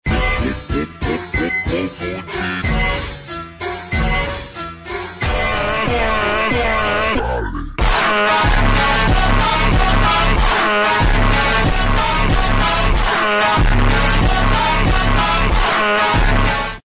Phonk Sound Effects MP3 Download Free - Quick Sounds